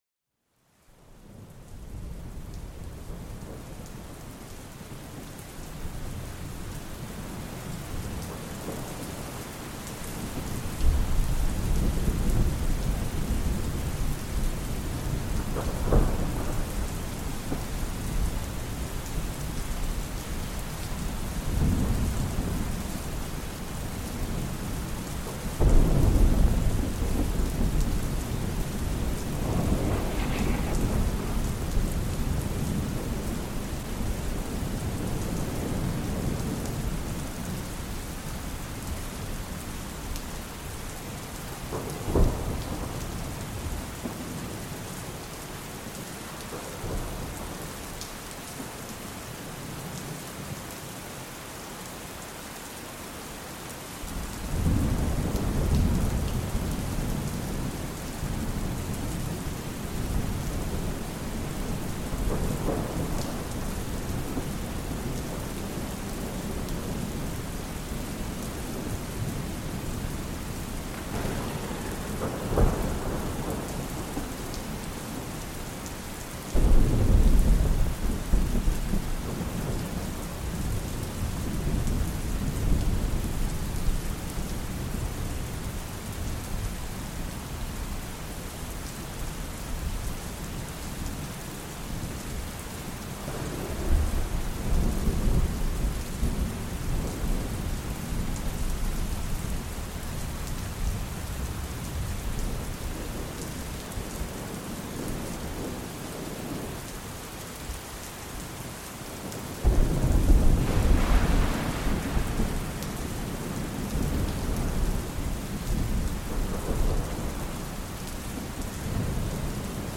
Sumérgete en el corazón de una tormenta poderosa, donde el crujido del rayo y el rugido del trueno se combinan en una sinfonía natural impresionante. Déjate envolver por la intensidad de la tormenta, un espectáculo sonoro que despierta los sentidos mientras invita a una relajación profunda.